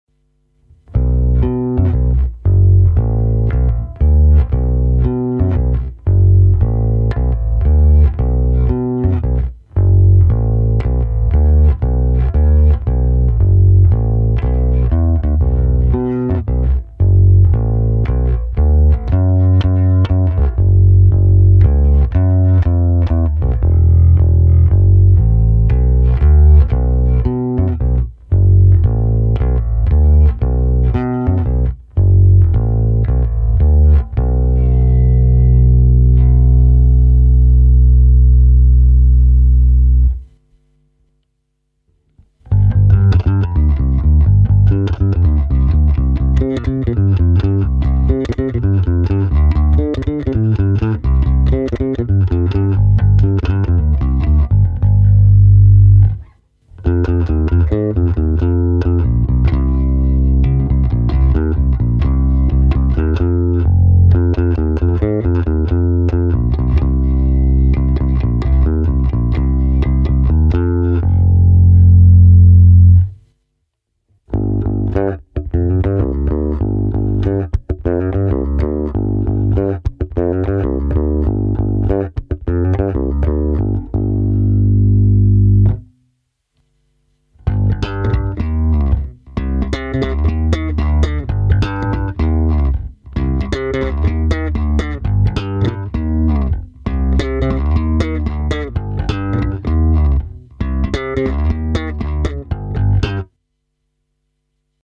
Nahrávka s hlazenými strunami Thomastik, čili ne moc reprezentativní, roundy by byly lepší. Hráno na oba snímače, nepatrně přidané basy a středy. Pořadí: krkový snímač - dvě ukázky na oba snímače - kobylkový snímač - slap na oba snímače.
To samé se simulací aparátu